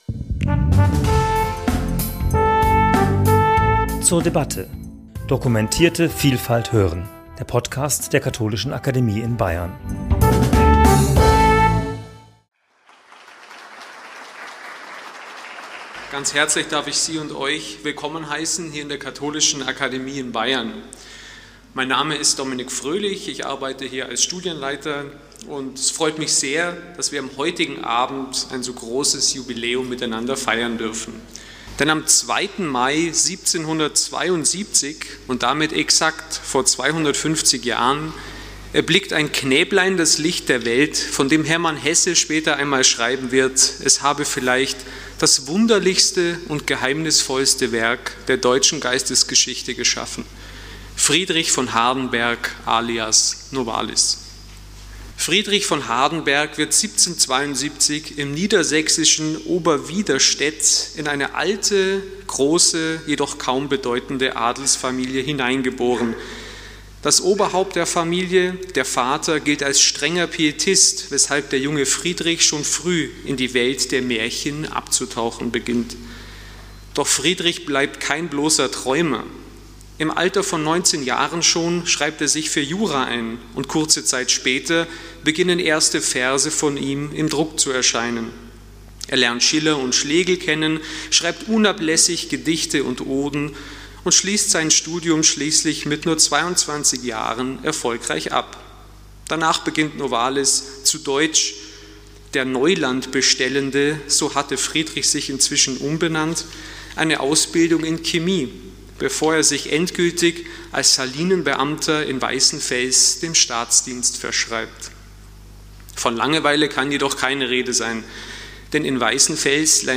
referierte am 2.5.2022 in der Katholischen Akademie in Bayern